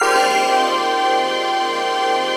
DM PAD2-33.wav